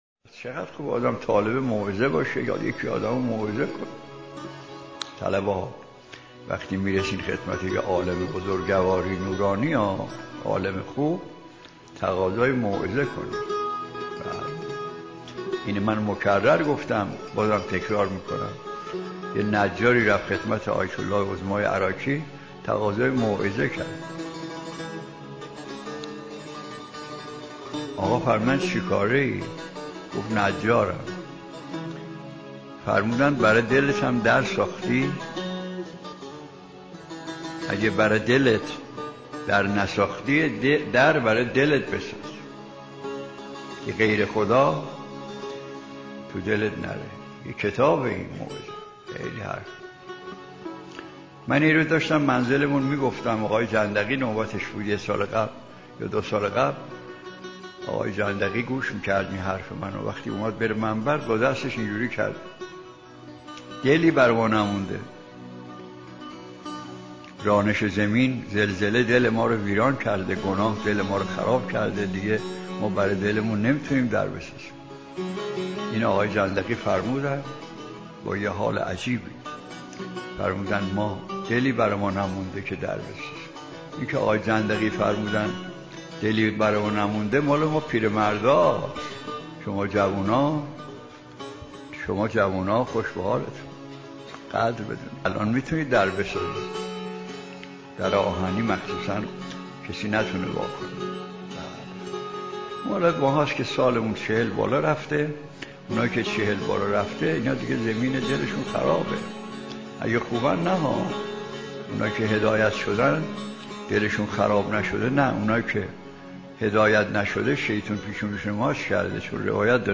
• موعظه, آیت الله مجتهدی تهرانی